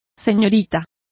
Complete with pronunciation of the translation of miss.